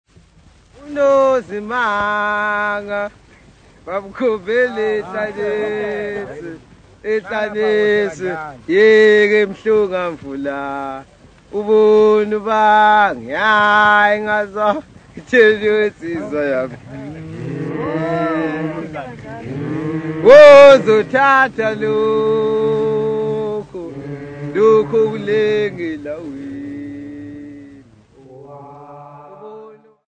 Folk music
Field recordings
sound recording-musical
Indigenous folk song with singing and humming